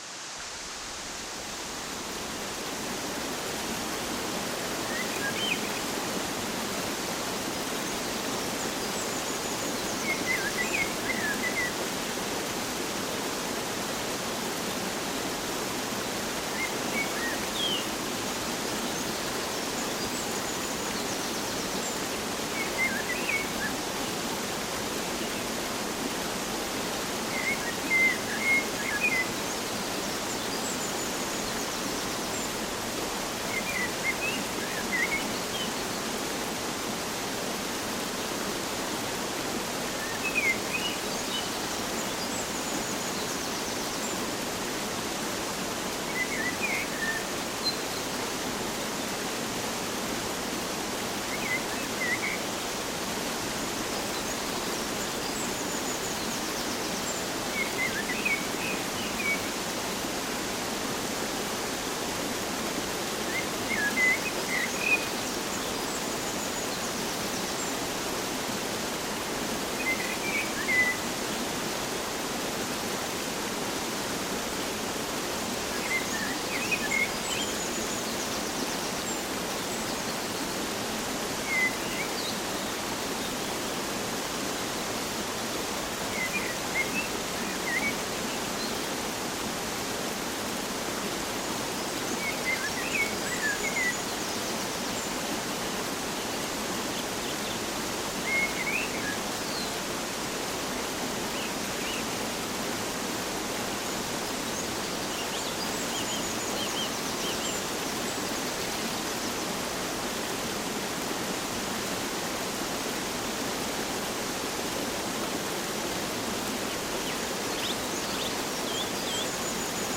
Naturgeräusche